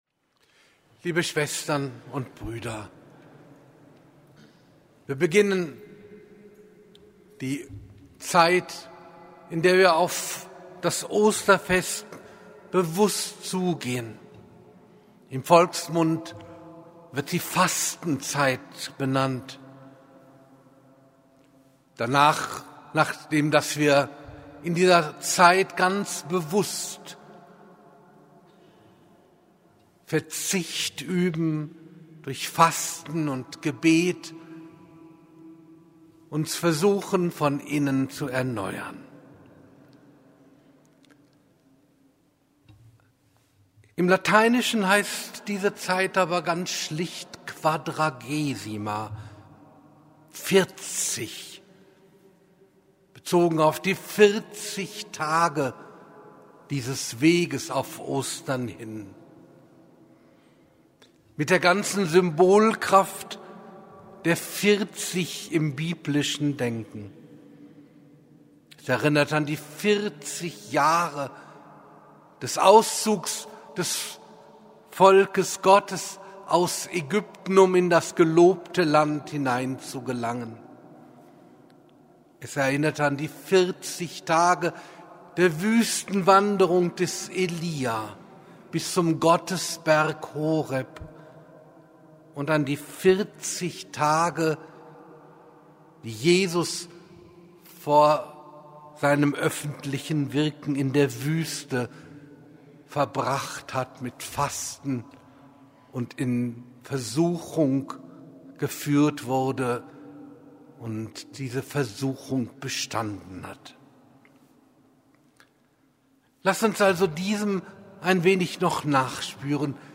Predigten von Bischof Dr. Karl-Heinz Wiesemann als Audio
Predigtmitschnitt zum Pontifikalamt Aschermittwoch 2026